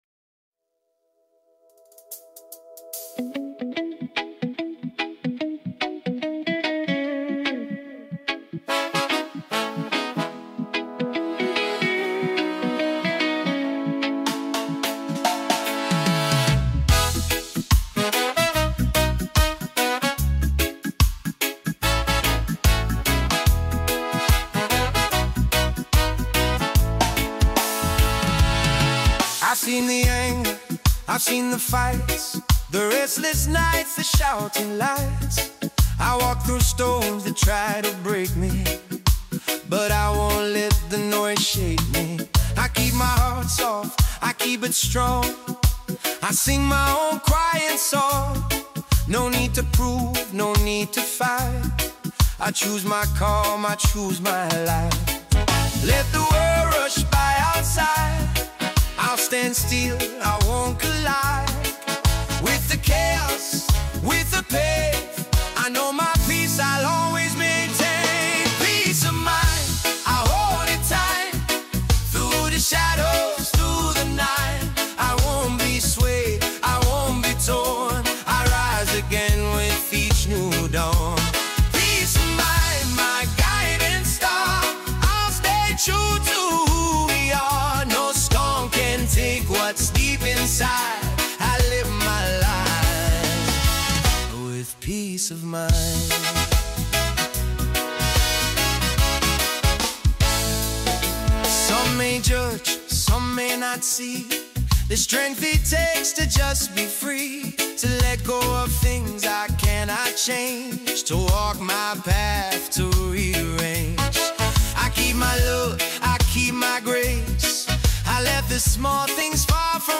There is a steady calm in how this track moves.
As it continues, the mood becomes more grounded.